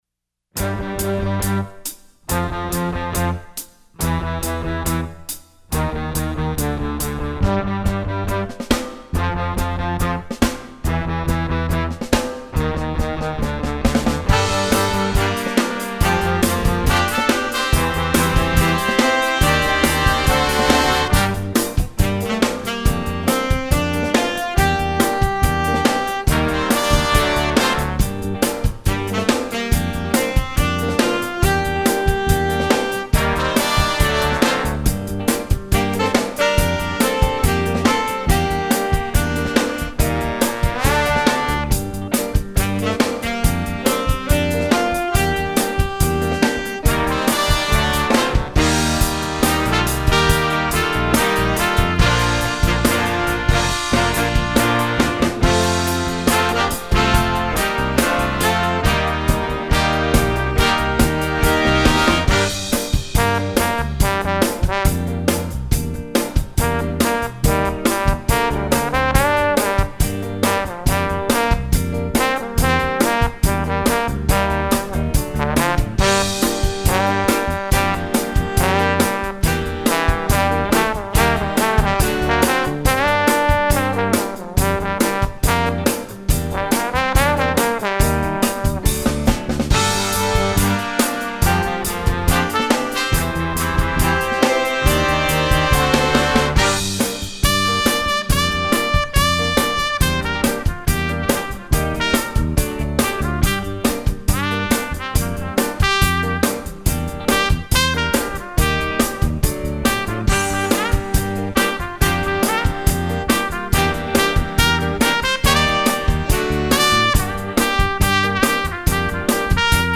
jazz, rock